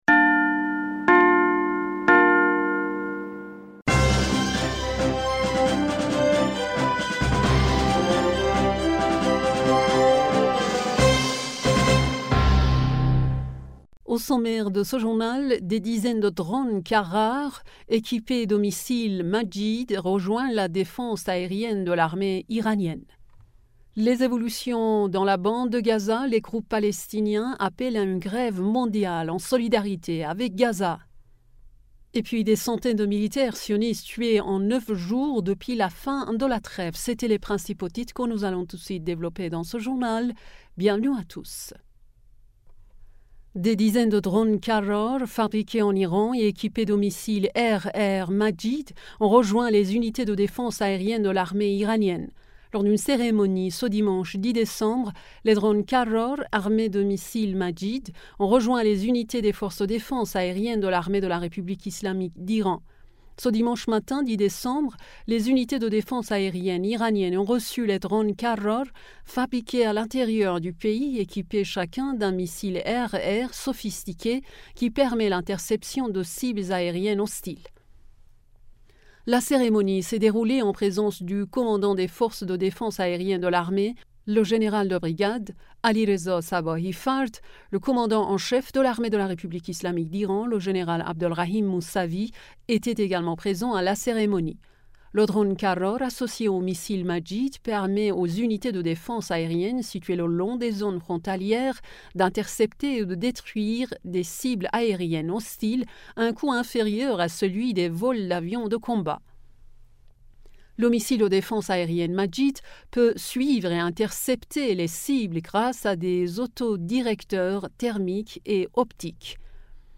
Bulletin d'information du 10 Decembre 2023